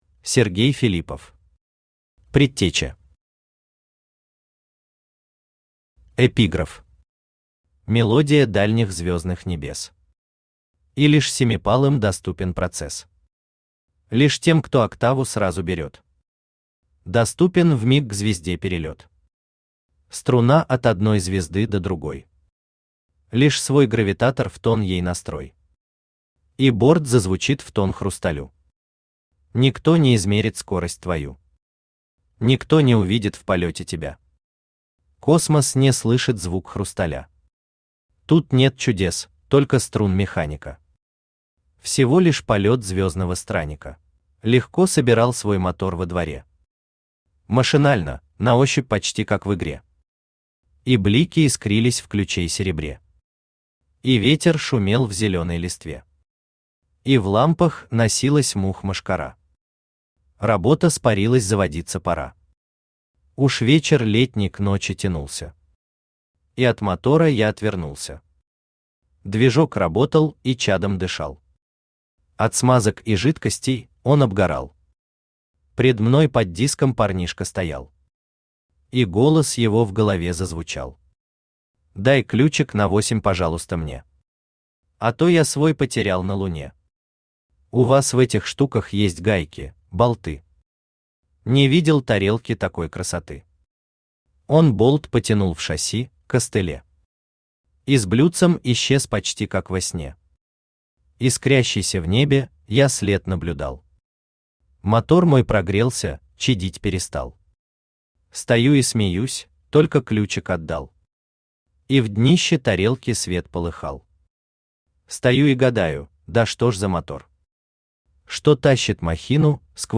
Аудиокнига Предтеча | Библиотека аудиокниг
Aудиокнига Предтеча Автор Сергей Владимирович Филиппов Читает аудиокнигу Авточтец ЛитРес.